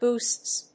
/busts(米国英語), bu:sts(英国英語)/